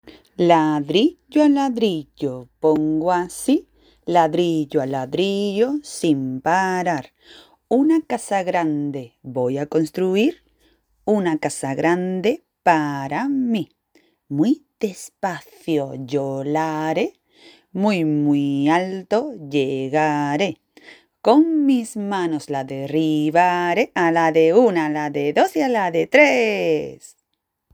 Doble.